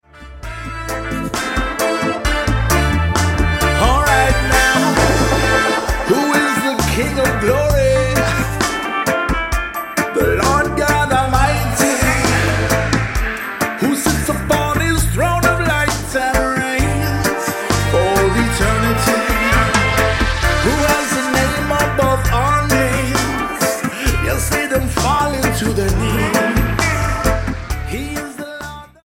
STYLE: Reggae